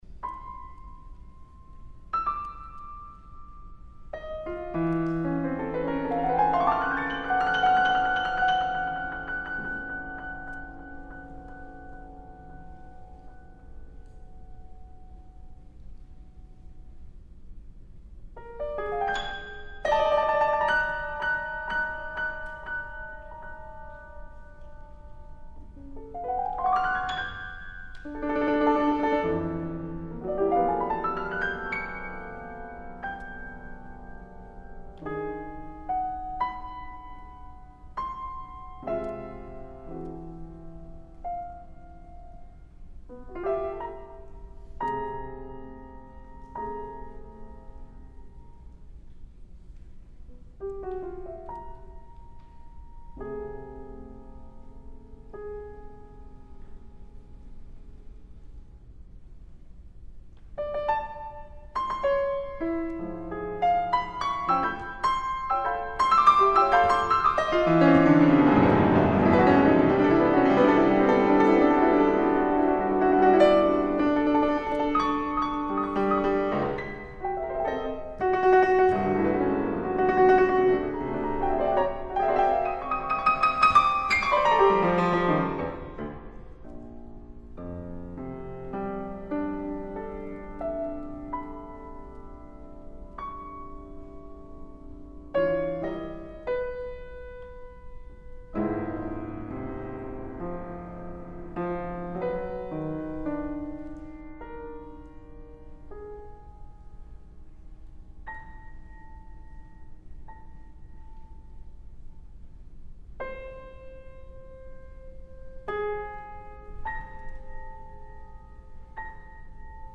for piano solo